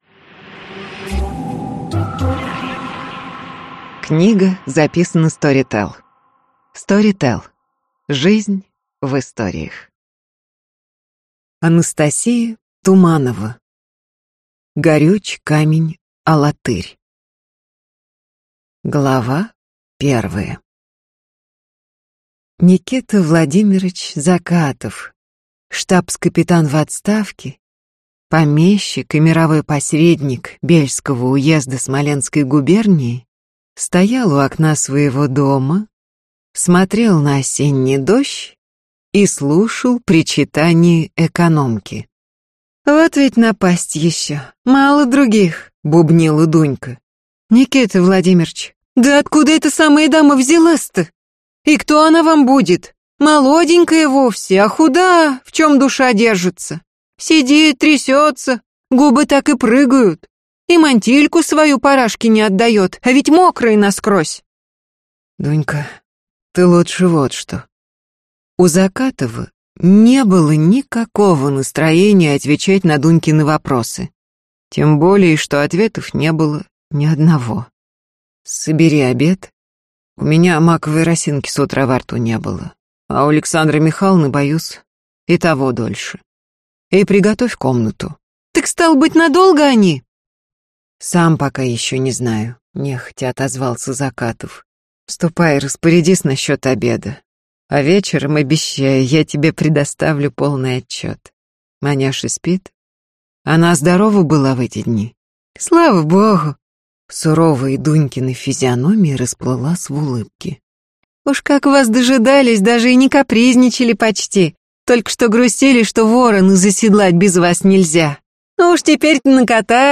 Аудиокнига Горюч камень Алатырь | Библиотека аудиокниг